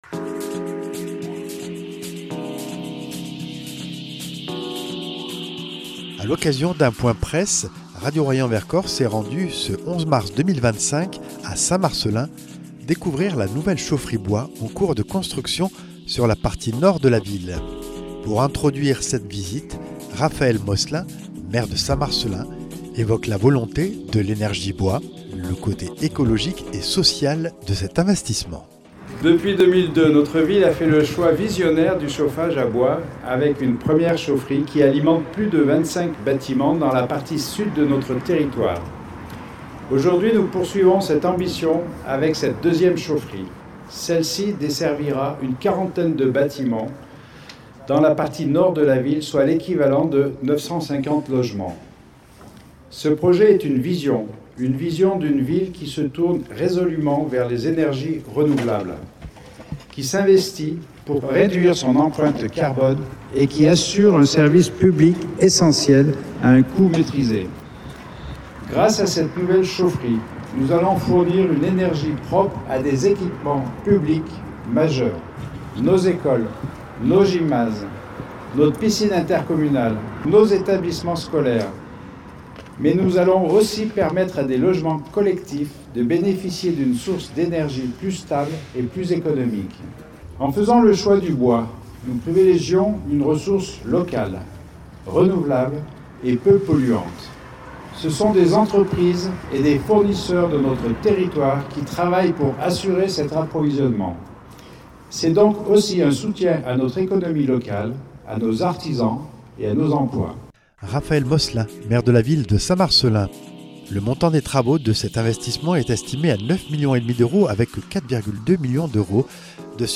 Élus et techniciens se relaient pour une visite du chantier à la presse.